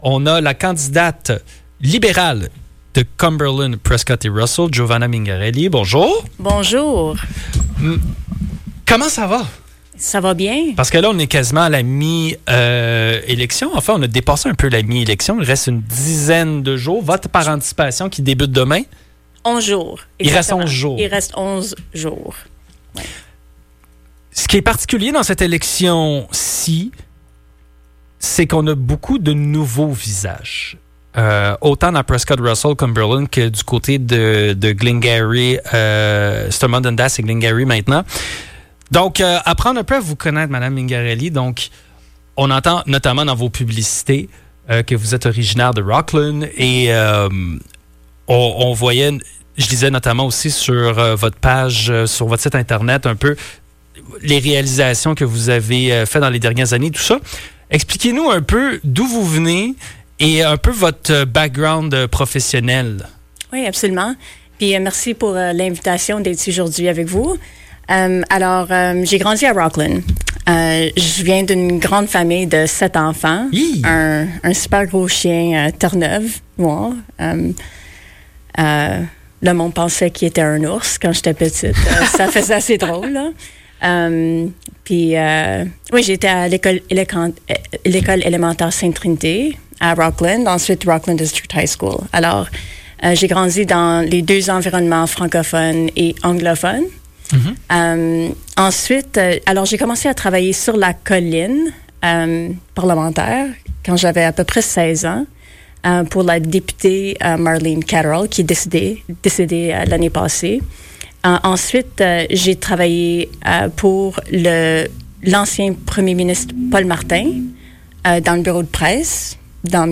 était de passage en studio.